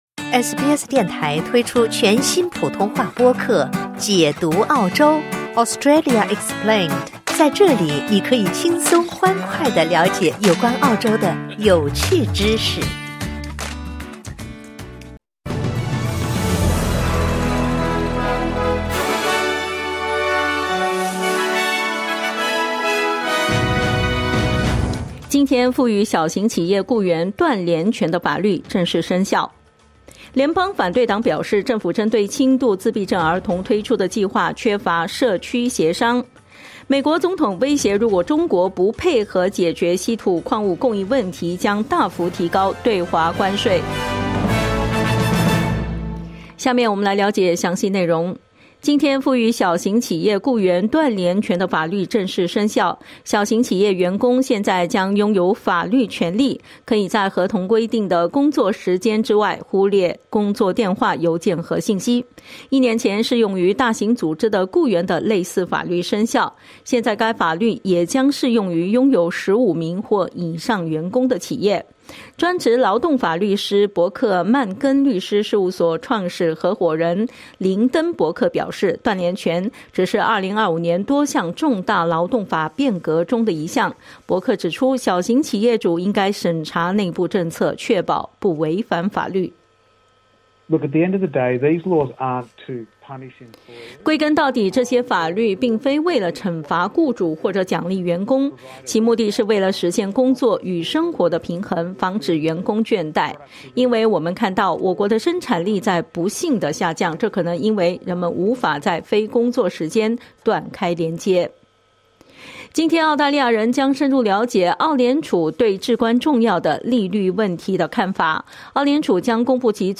SBS早新闻（2025年8月26日）